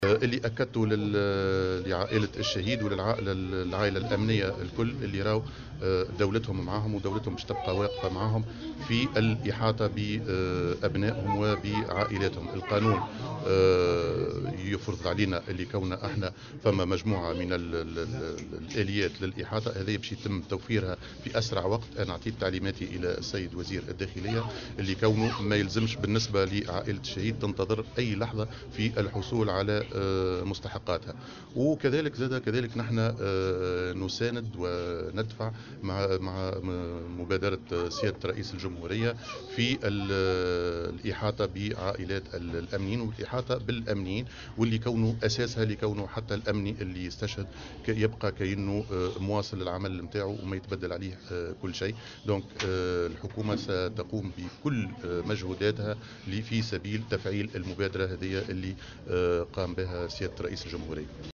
وأضاف في تصريح اليوم لمراسل "الجوهرة أف أم"